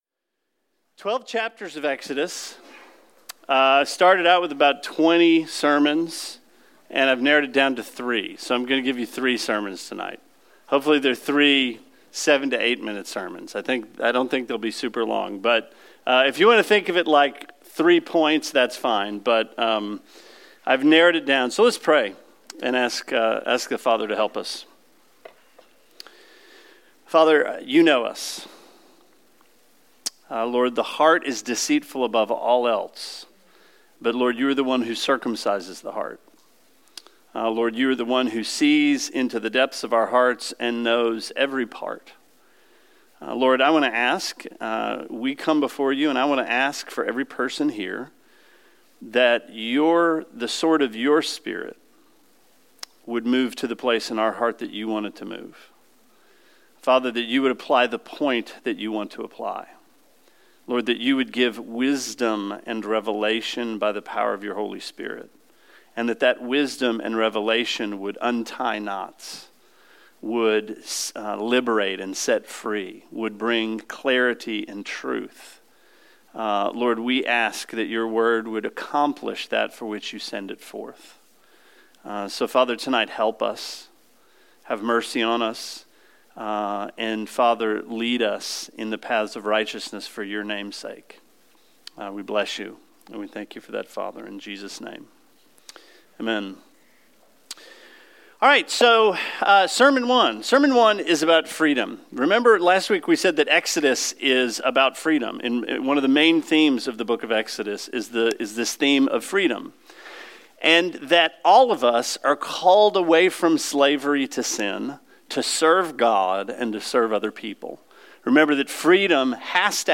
Sermon 06/14: Slavery in Exodus